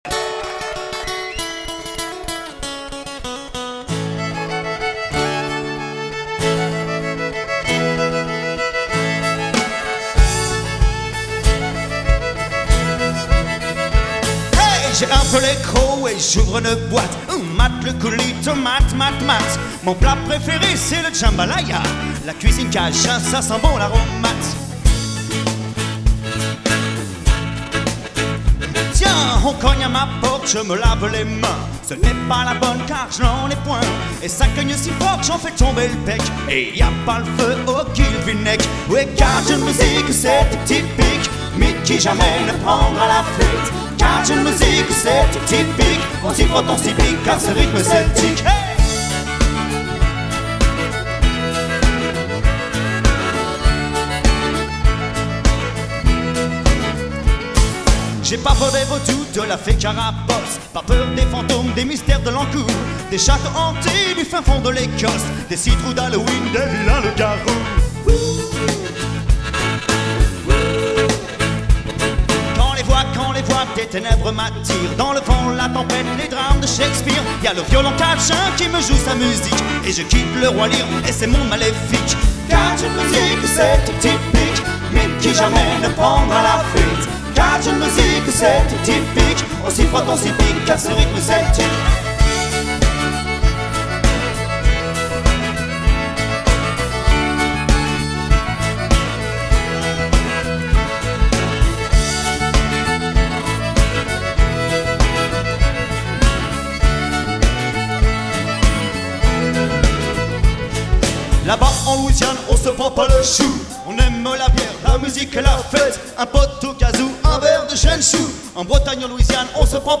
Début de la danse après une intro de 24 comptes